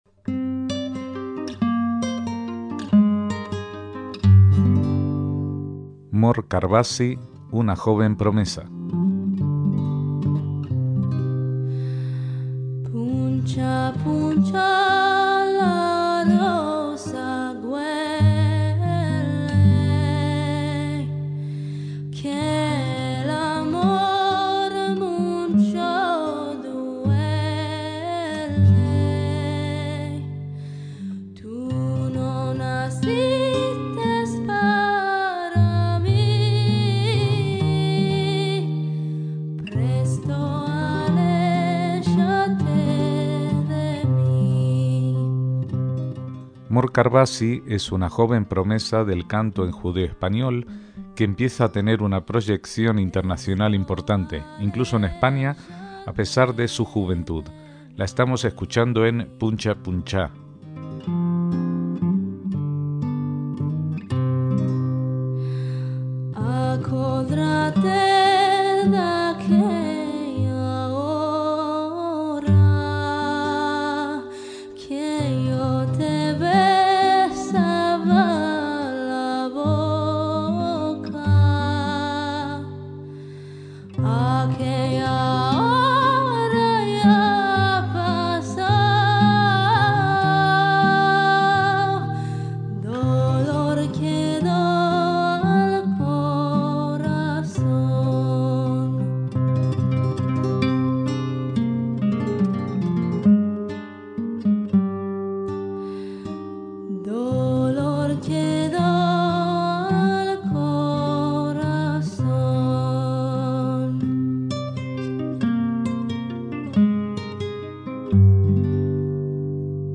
MÚSICA SEFARDÍ
una cantante israelí
música sefardí